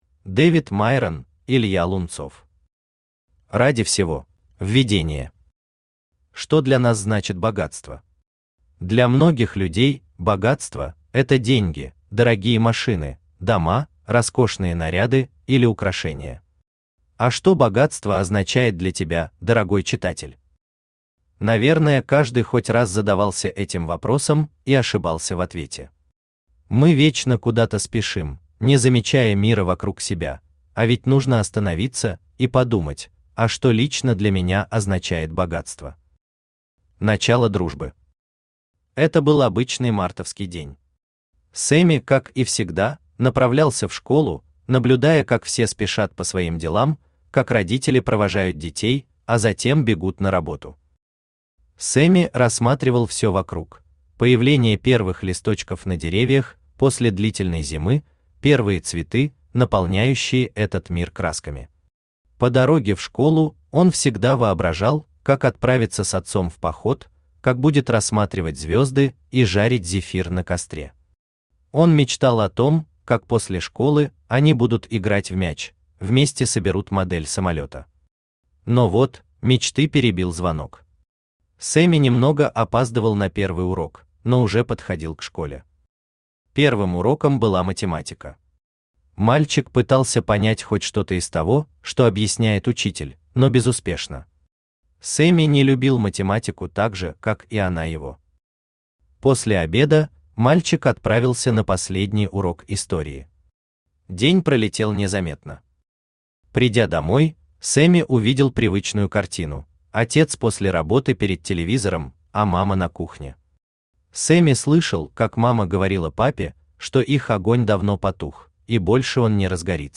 Аудиокнига Ради всего | Библиотека аудиокниг
Aудиокнига Ради всего Автор Дэвид Майрон Читает аудиокнигу Авточтец ЛитРес.